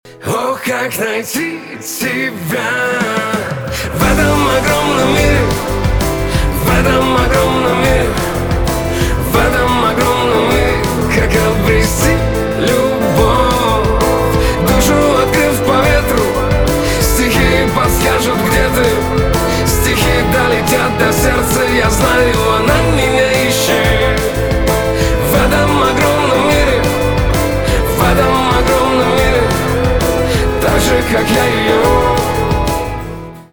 поп
нарастающие , барабаны , гитара
чувственные